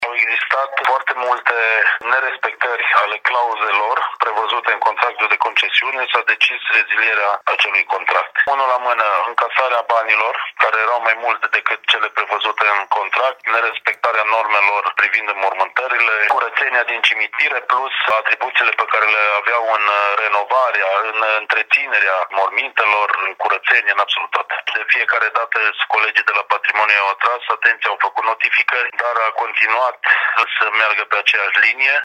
Societatea în cauză a încălcat, în mod repetat, clauzele contractuale, spune viceprimarul Cosmin Tabără. În plus, timișorenii au semnalat și faptul că firma încasa tarife mai mari decât cele aprobate de consiliul local, mai spune liberalul.